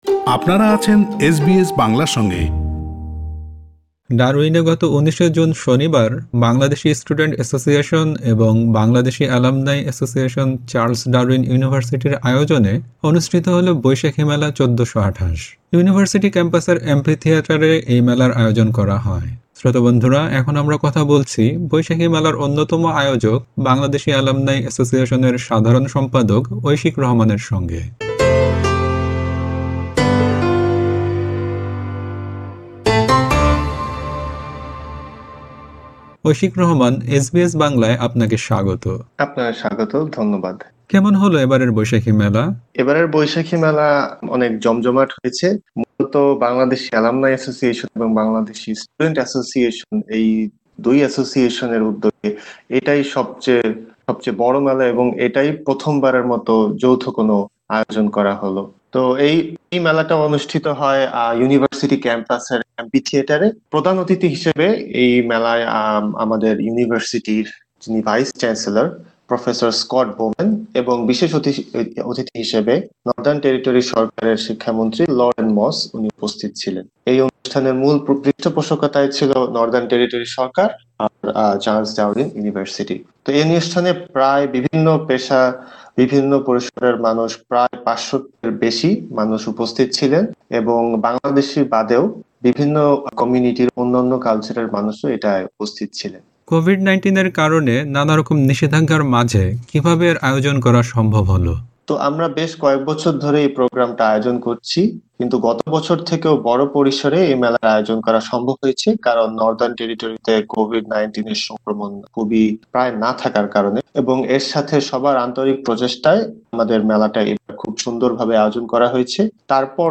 কথা বলেছেন এসবিএস বাংলার সঙ্গে।